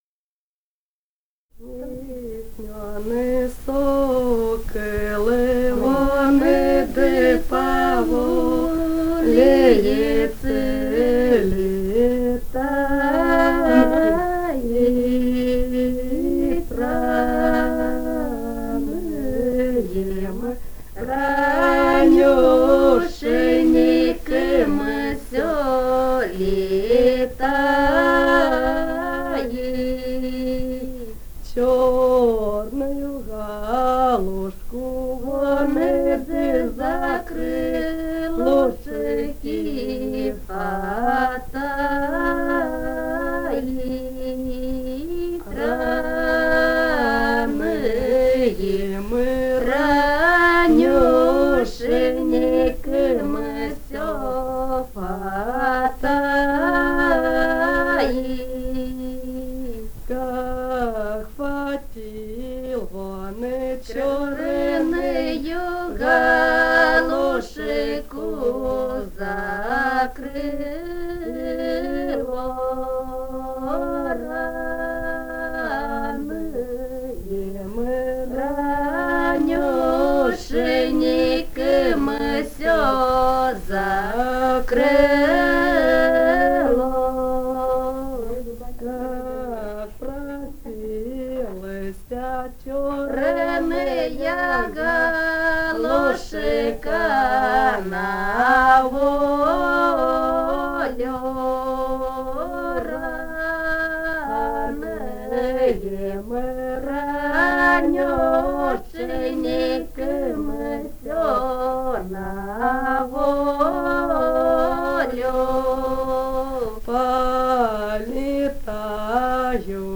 полевые материалы
«Не ясмён сокол» (свадебная).
Румыния, с. Переправа, 1967 г. И0973-16